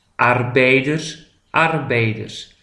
de arbeider PRONONCIATION